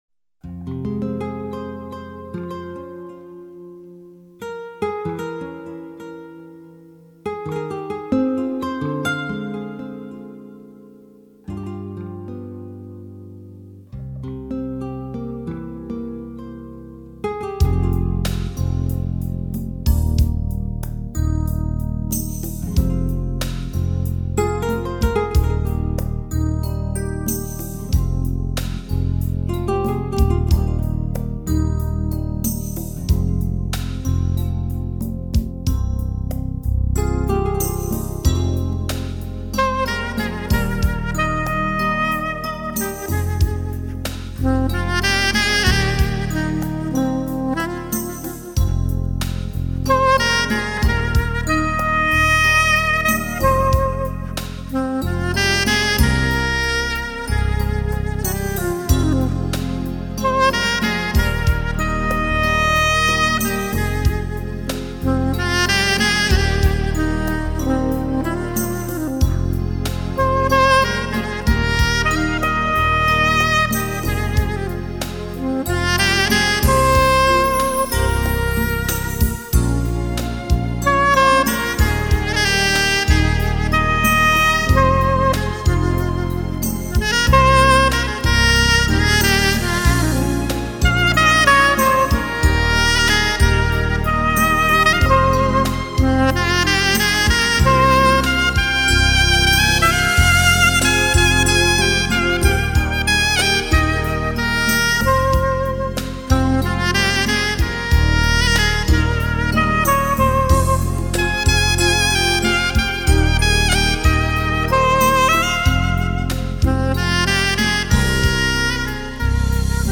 اهنگ بیکلام
موسیقی جاز